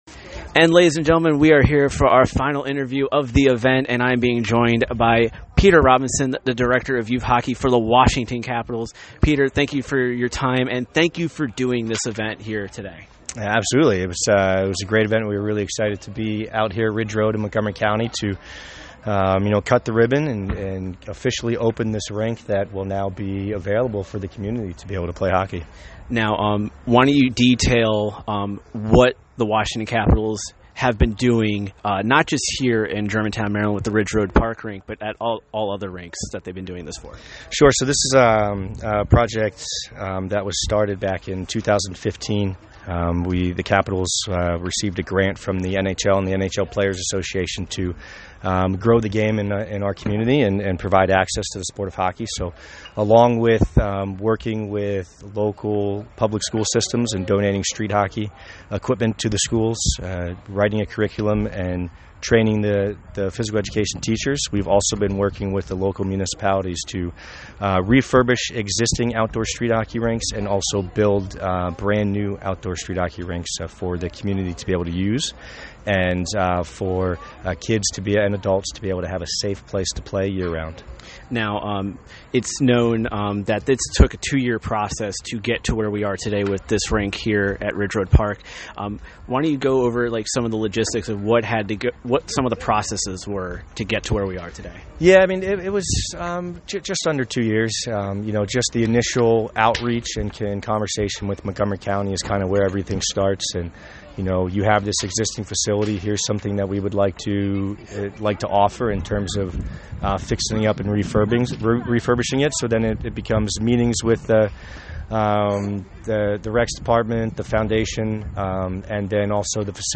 During the event I was able to speak with some of the key people that helped make today possible, and here are our interviews with them.